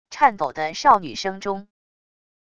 颤抖的少女声中wav音频